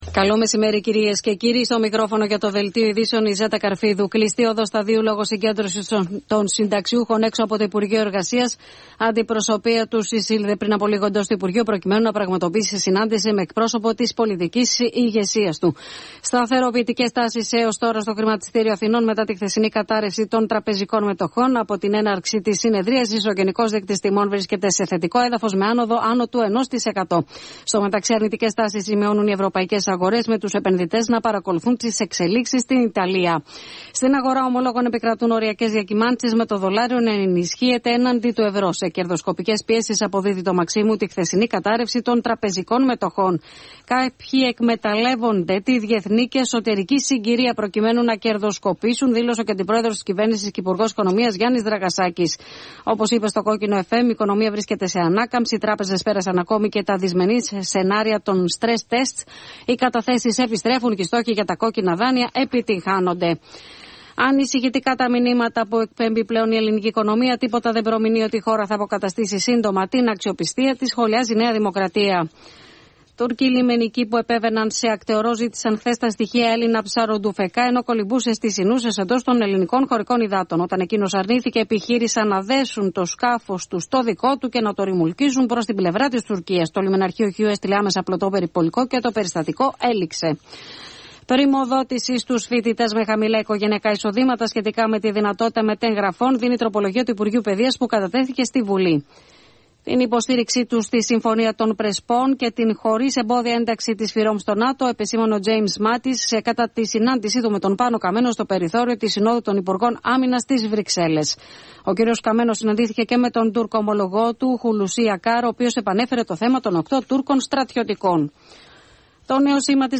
Δελτίο ειδήσεων στις 12 00